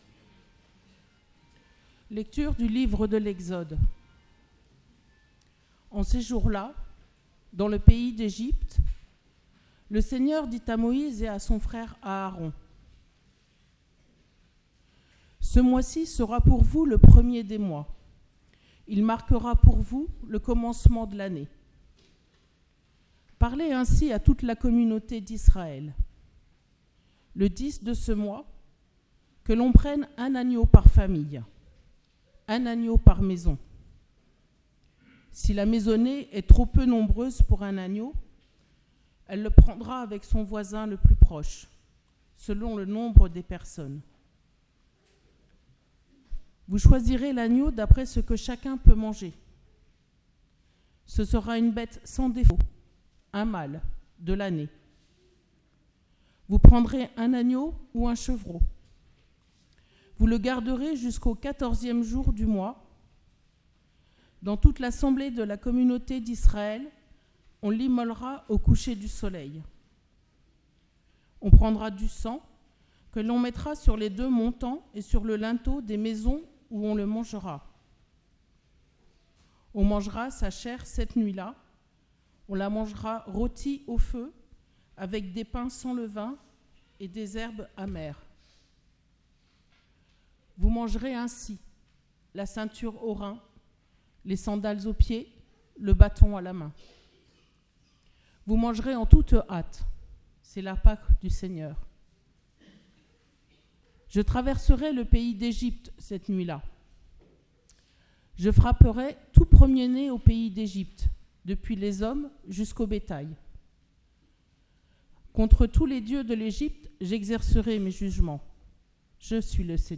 Parole de Dieu & Sermon du 6 Avril 2023, Jeudi Saint